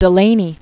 (də-lānē), Martin Robinson 1812-1885.